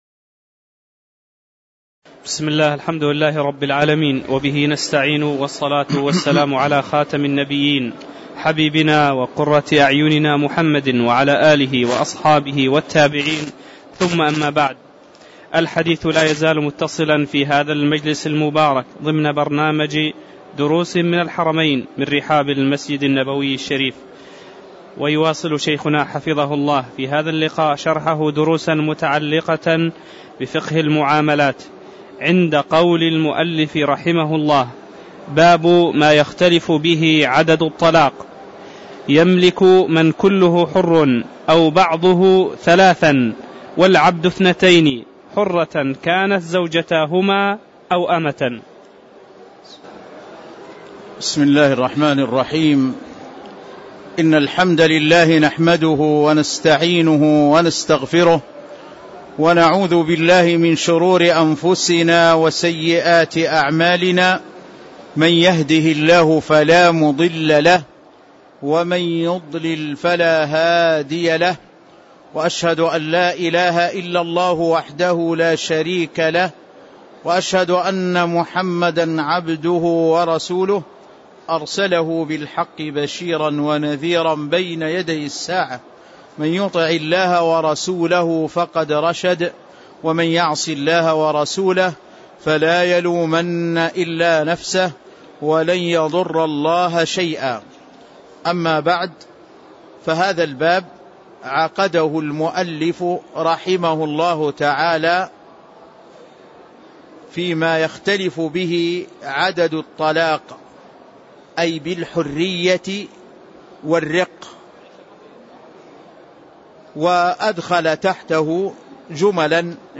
تاريخ النشر ٢٧ جمادى الآخرة ١٤٣٧ هـ المكان: المسجد النبوي الشيخ